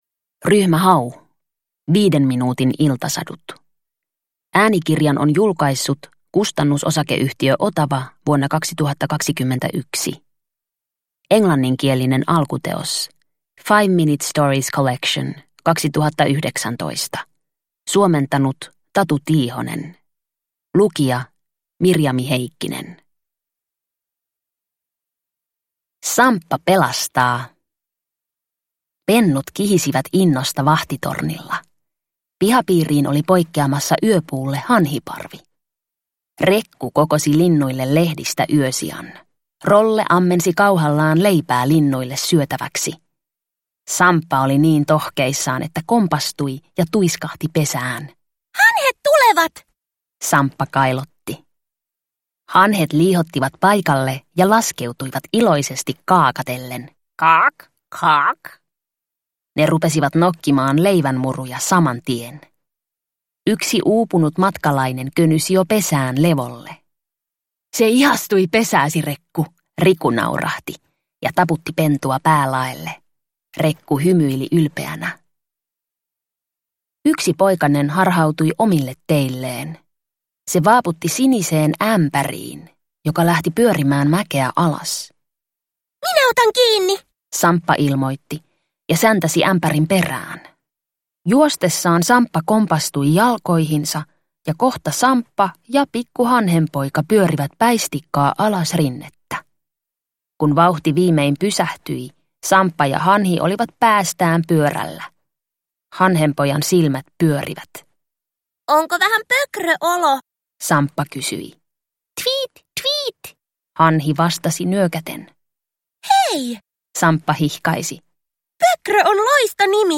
Ryhmä Hau - 5 minuutin iltasadut – Ljudbok – Laddas ner